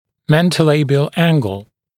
[ˌmentəu’leɪbɪəl ‘æŋgl][ˌмэнтоу’лэйбиэл ‘энгл]подбородочный угол